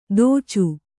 ♪ dōcu